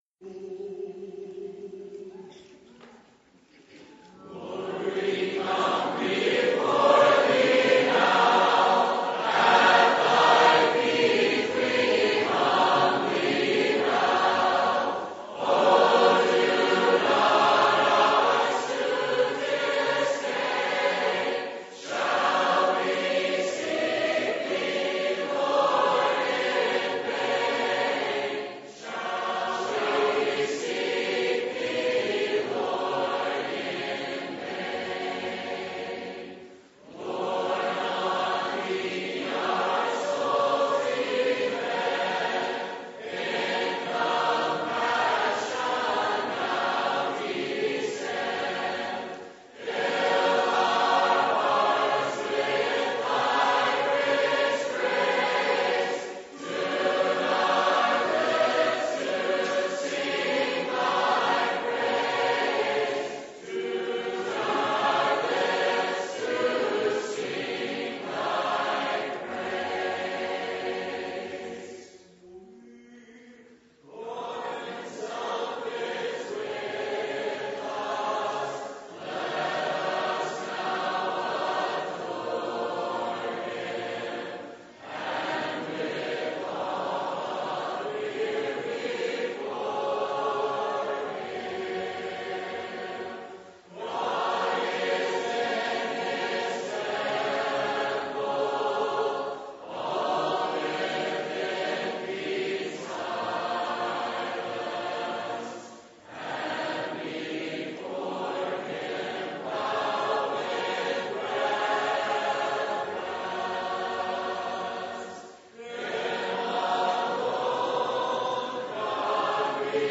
InHisPresence2014Singing.mp3